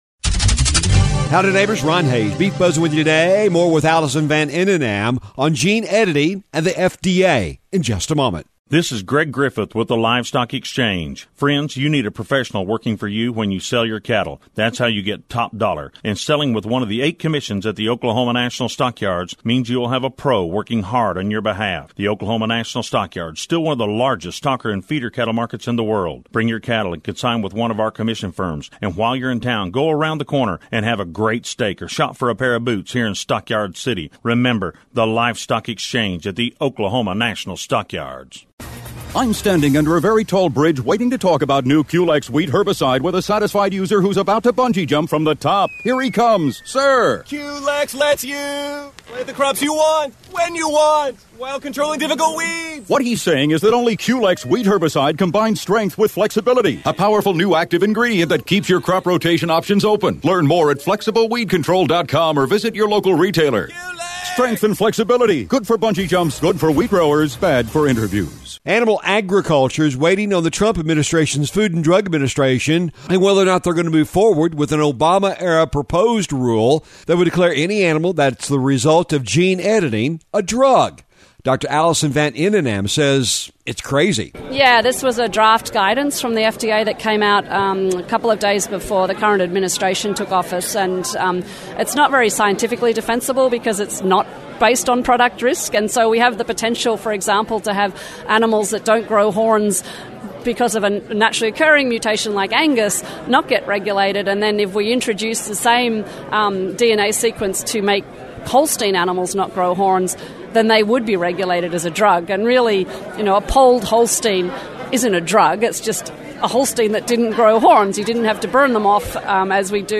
The Beef Buzz is a regular feature heard on radio stations around the region on the Radio Oklahoma Network and is a regular audio feature found on this website as well.